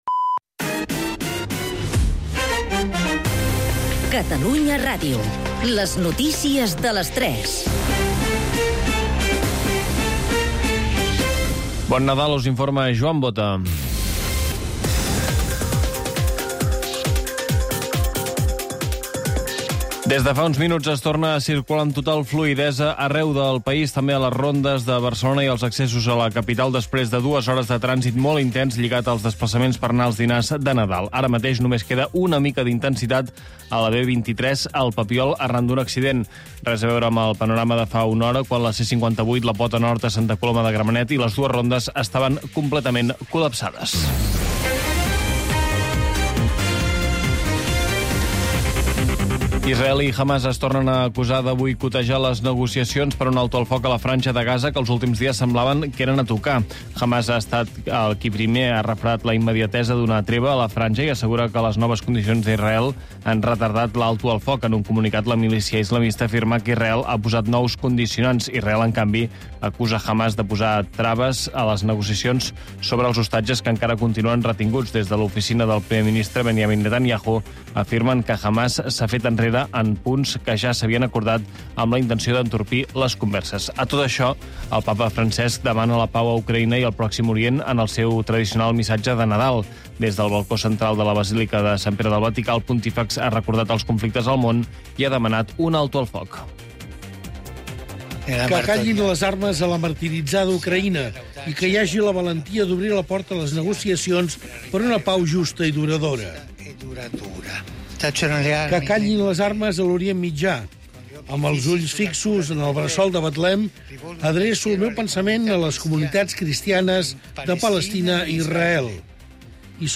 … continue reading 503 에피소드 # Society # Corporaci Catalana de Mitjans Audiovisuals, SA # Catalunya Rdio # News Talk # News